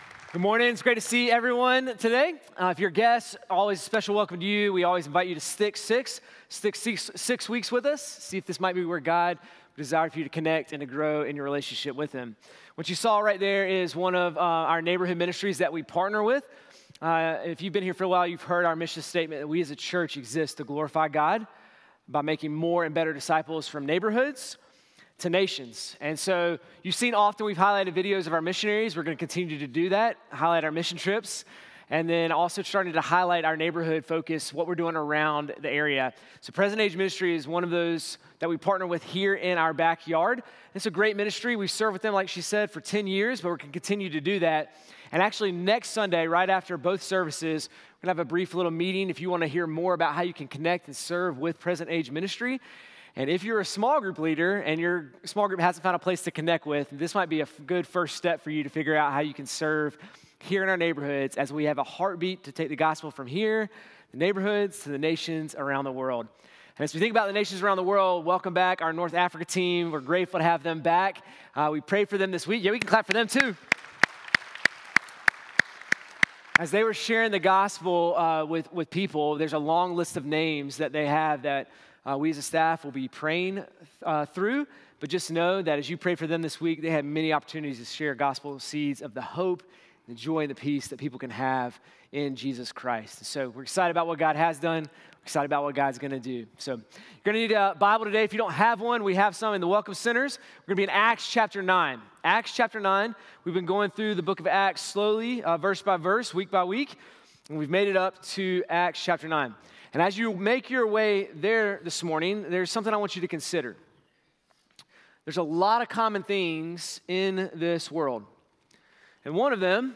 sermon-4-19-26.mp3